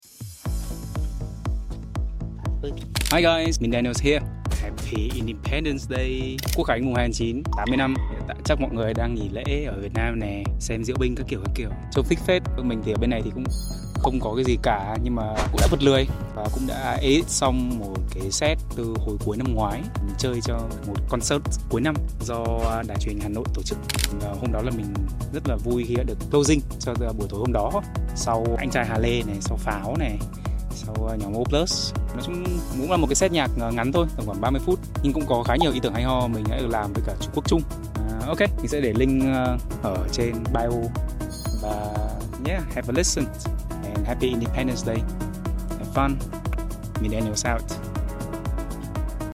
closing concert